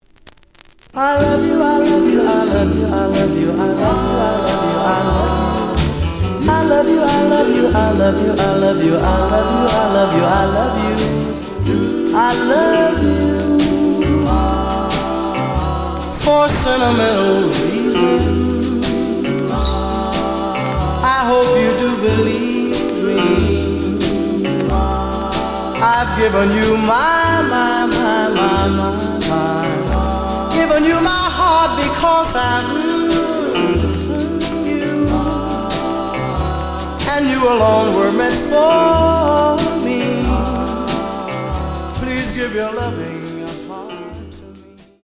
The audio sample is recorded from the actual item.
●Genre: Soul, 60's Soul